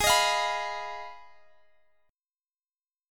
Abm6 Chord
Listen to Abm6 strummed